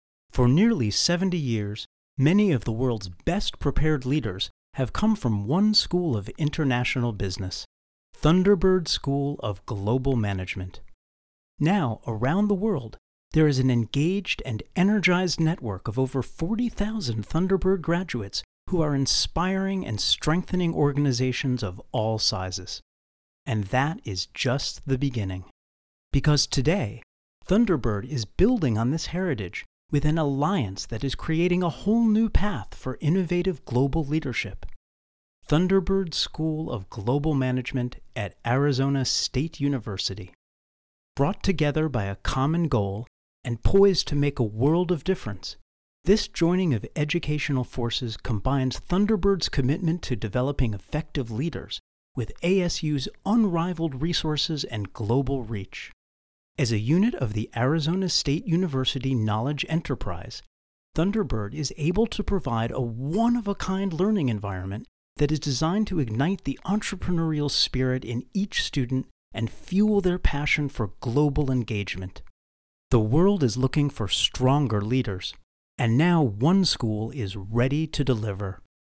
Engaging, Authentic, Articulate Storyteller
Thunderbird (radio spot)
General (Standard) American, Brooklyn
Middle Aged